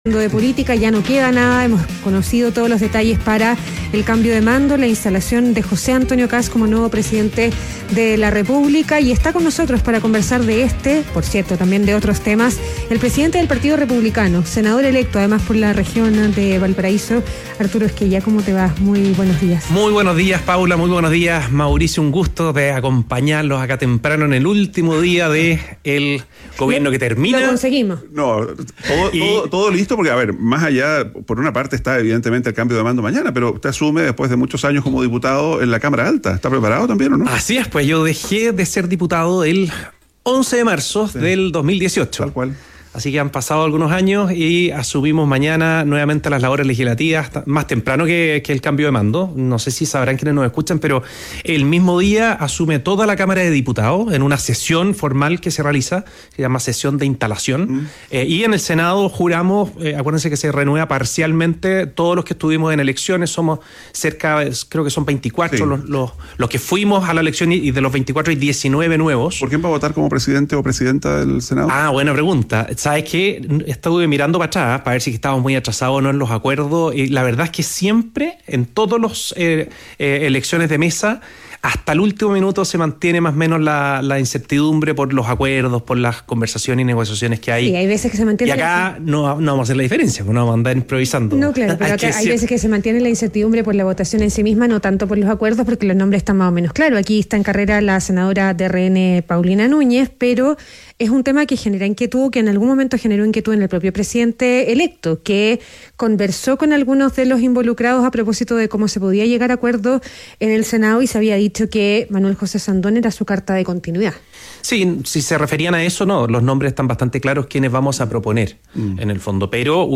ADN Hoy - Entrevista a Arturo Squella, senador electo y presidente del Partido Republicano